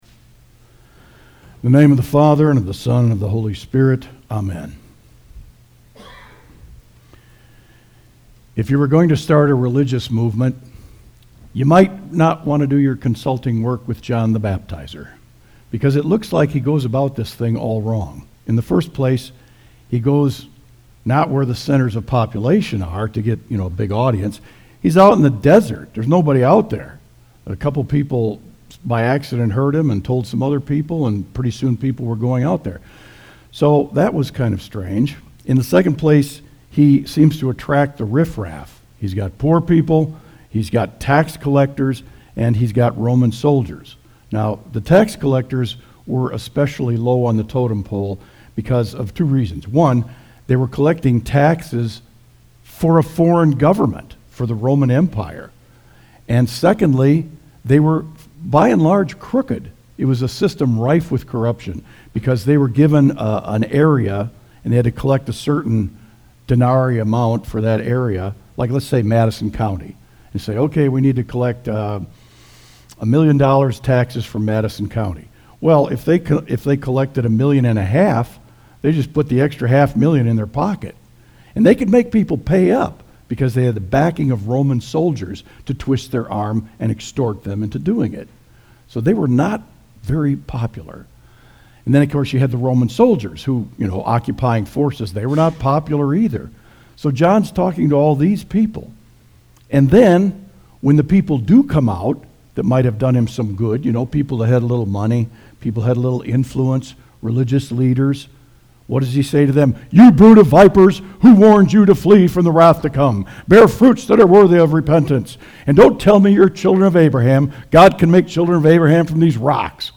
Audio Sermon “What Should We Do?”